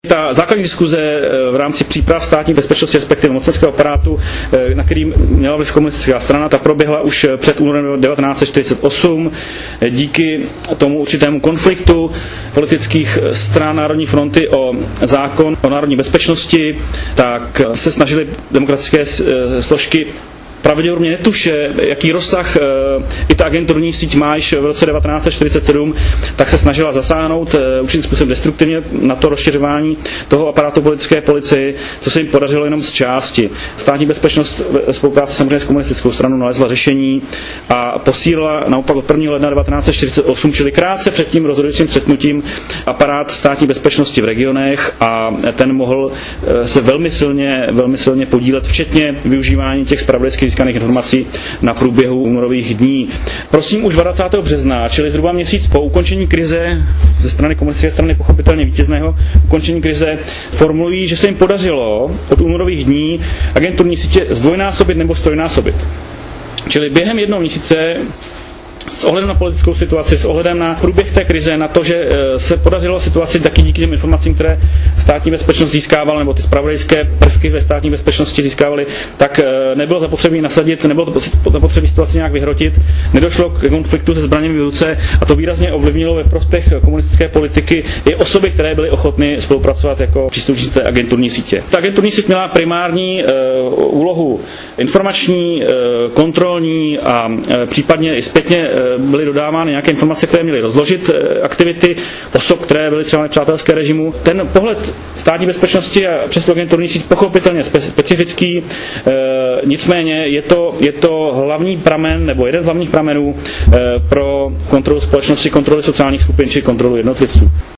Milan Paumer hovoří o motivaci k odbojové činnostii